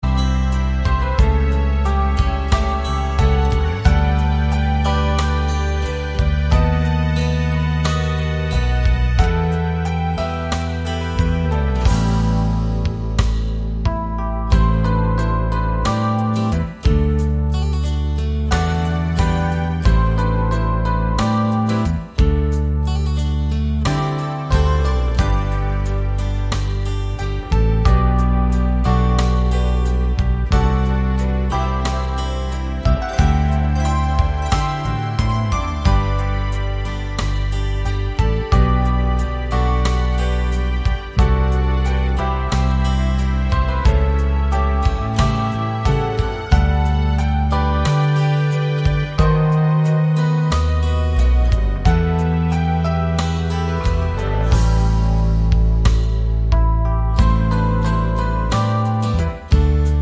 no Backing Vocals Soundtracks 4:17 Buy £1.50